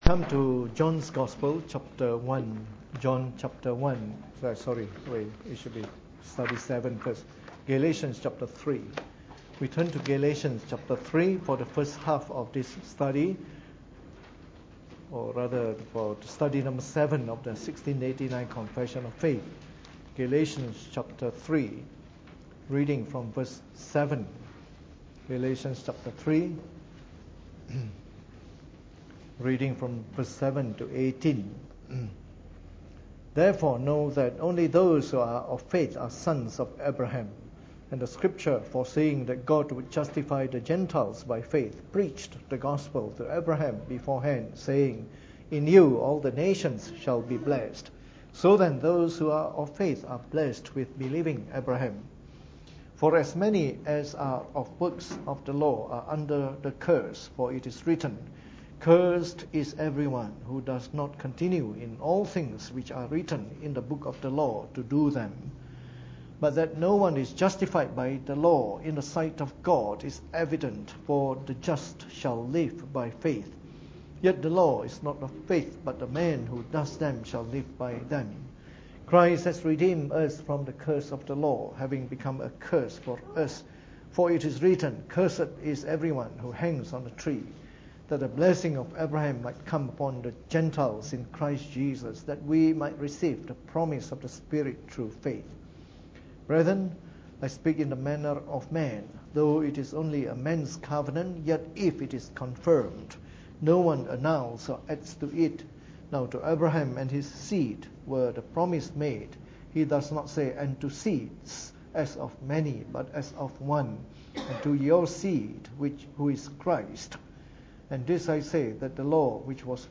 Preached on the 27th of April 2016 during the Bible Study, from our series on the Fundamentals of the Faith (following the 1689 Confession of Faith).